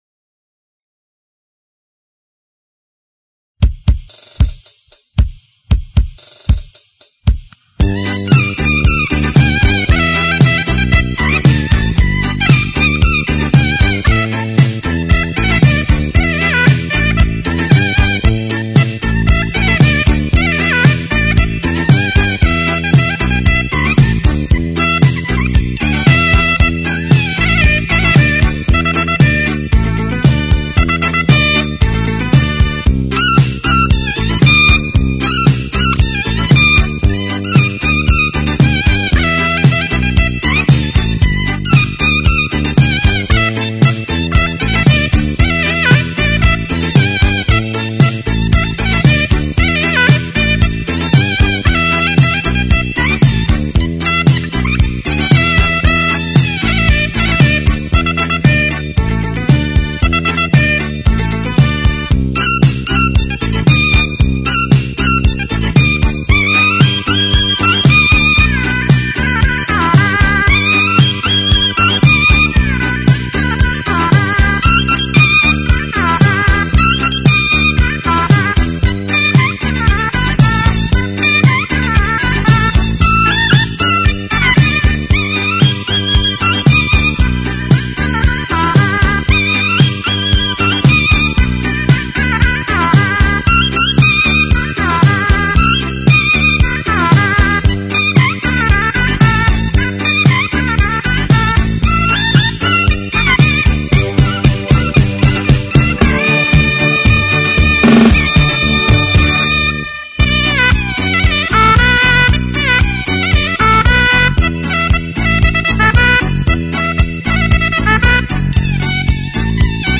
唢呐演奏